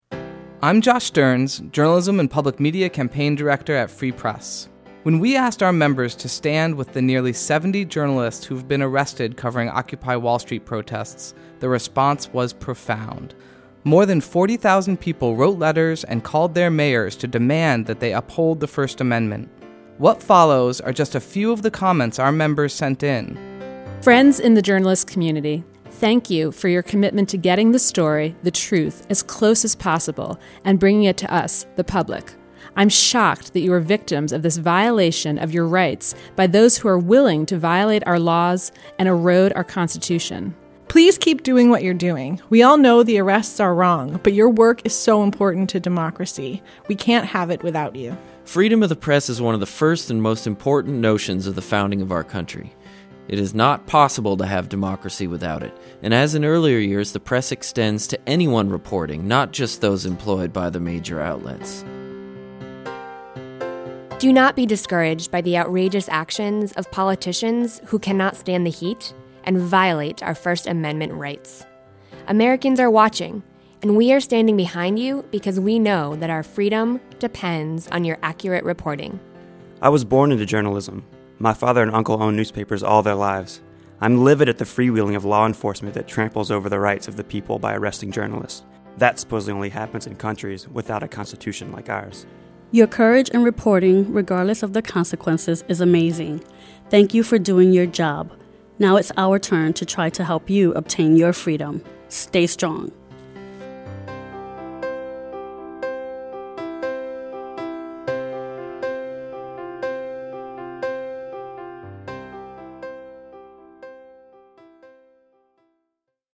as recorded by staff at Free Press: